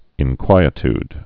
(ĭn-kwīĭ-td, -tyd)